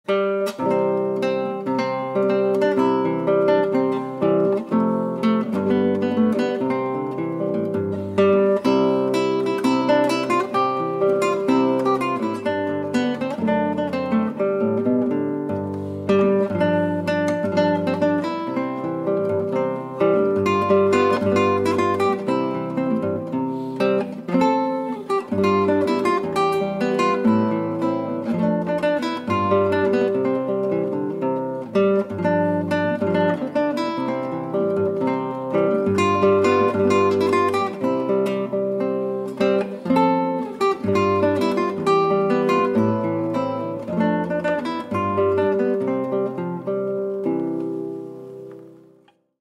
торжественная мелодия
инструментальная музыка
на гитаре акустической